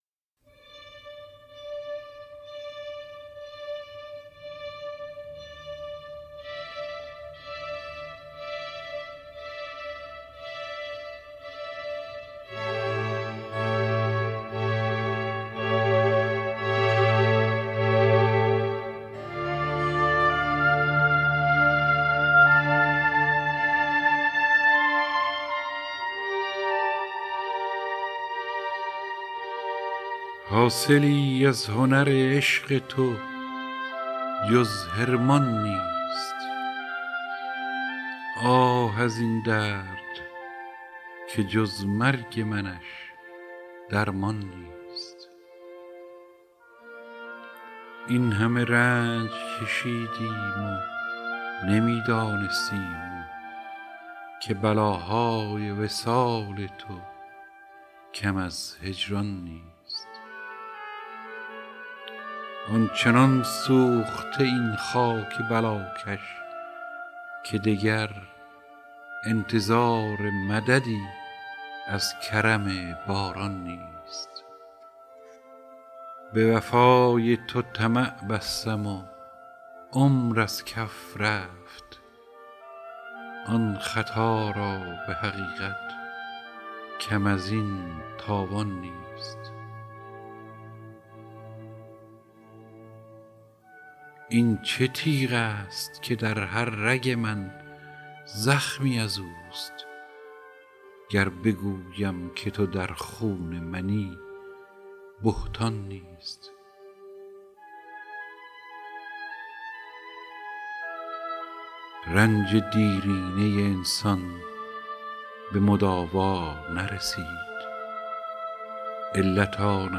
دانلود دکلمه رنج دیرینه با صدای هوشنگ ابتهاج